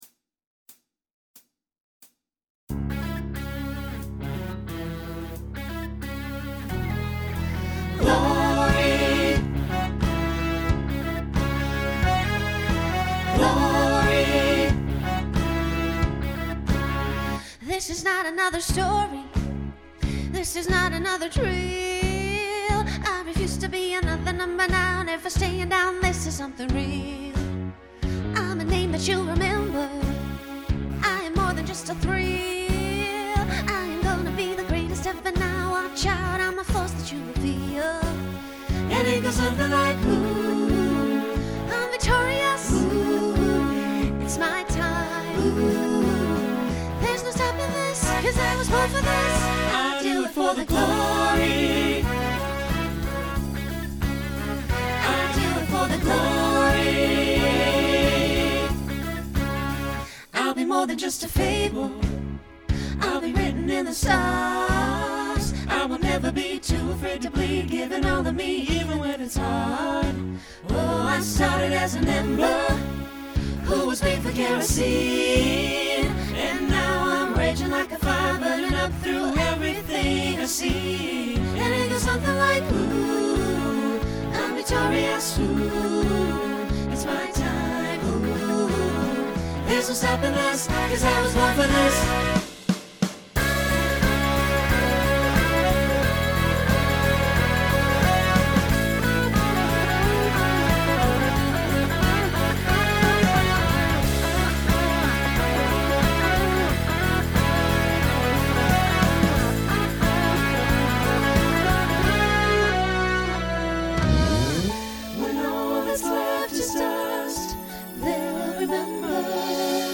Genre Rock Instrumental combo
Mid-tempo Voicing SATB